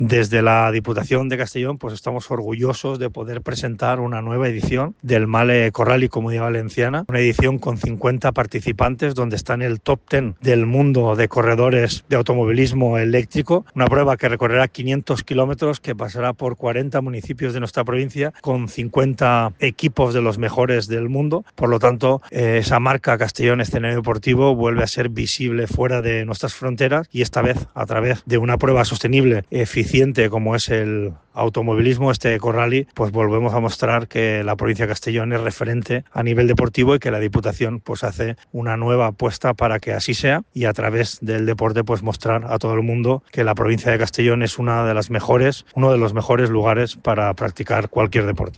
Diputado-Ivan-Sanchez-presentacion-Rallye-Comunitat-Valenciana.mp3